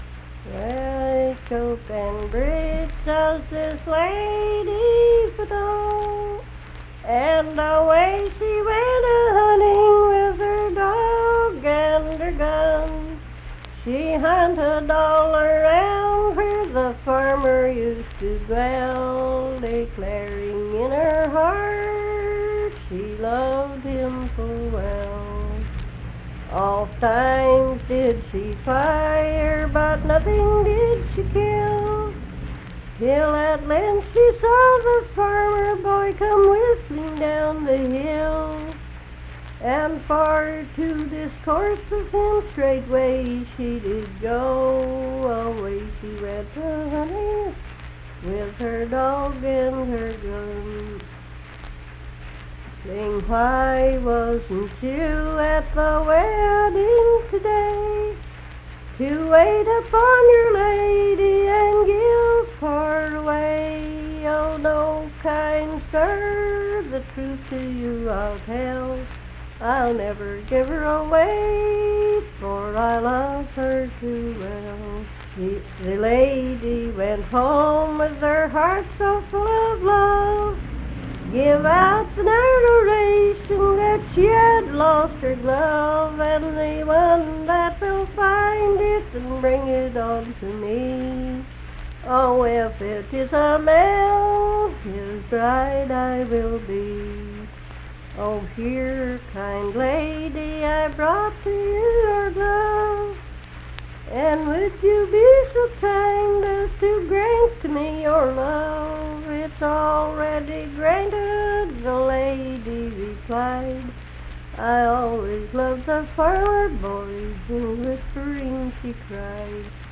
Unaccompanied vocal music performance
Verse-refrain 6(4).
Voice (sung)